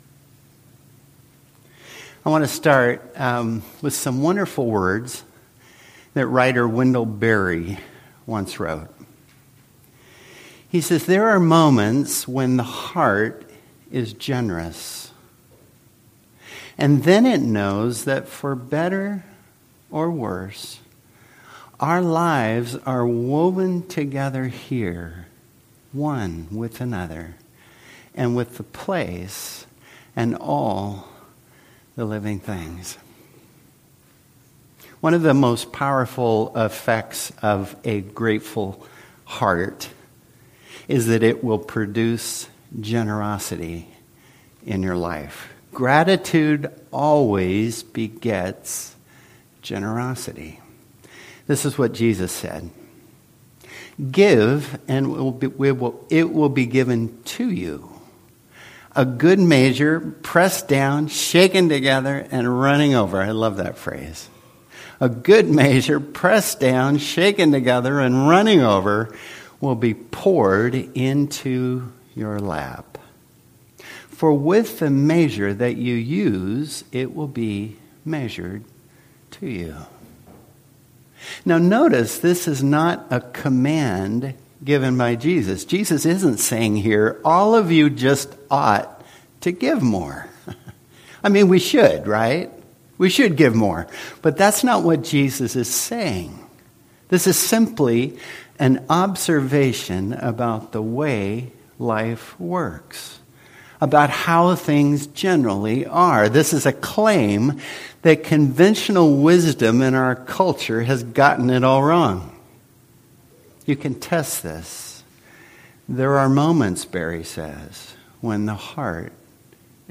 Service Type: Worship Service